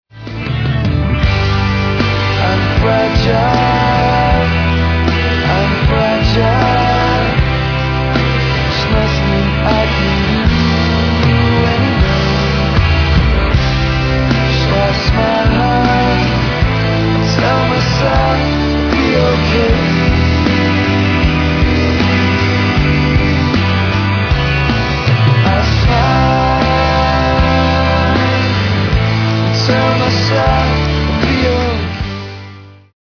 Vocals, Acoustic Guitar
Bass, Vocals, Accordion, Harmonium
Electric Guitar, Vocals, Pedal Steel
Drums, Vocals, Percussion, Tack Piano